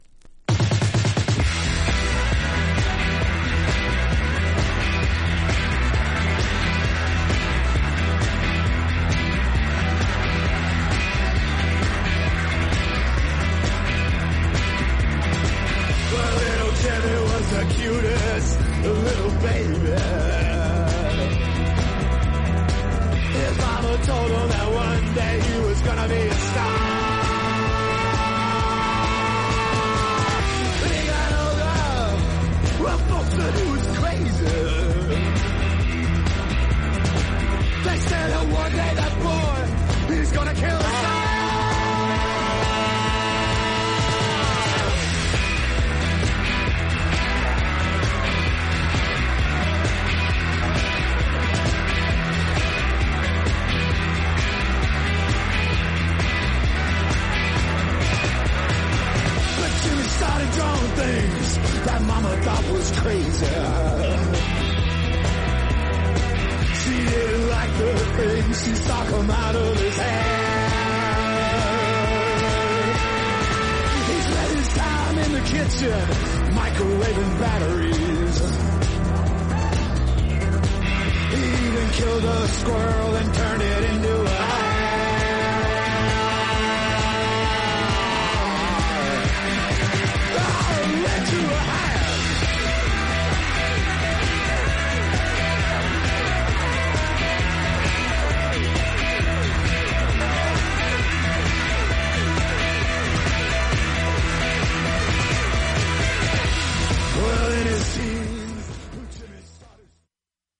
盤面ごく薄いスレが僅かにありますが音に影響無く綺麗です。
実際のレコードからのサンプル↓